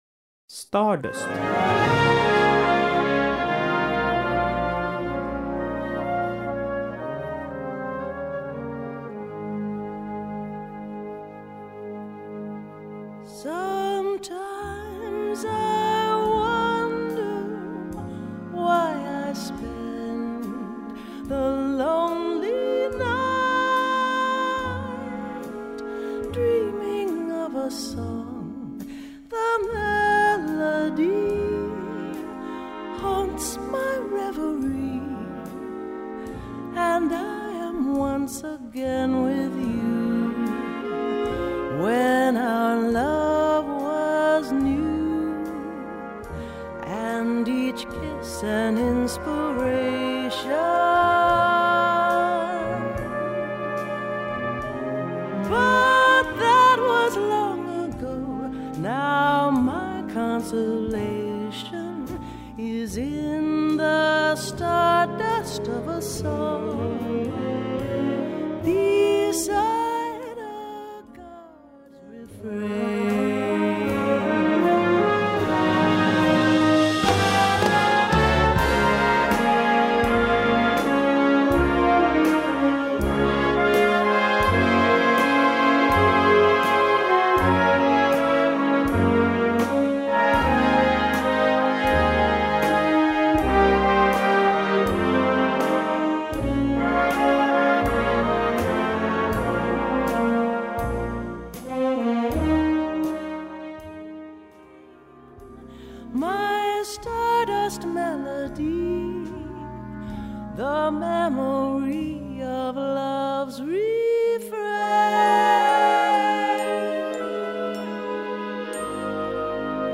Gattung: Solo Gesang, Altsaxophon, Klarinette o. Trompete
Besetzung: Blasorchester